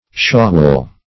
shawwal - definition of shawwal - synonyms, pronunciation, spelling from Free Dictionary